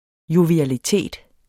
Udtale [ jovialiˈteˀd ]